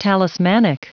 Prononciation du mot talismanic en anglais (fichier audio)
Prononciation du mot : talismanic